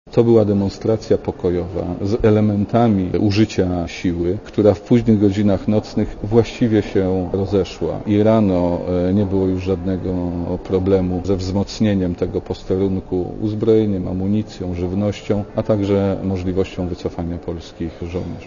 Posłuchaj komentarza Jerzego Szmajdzińskiego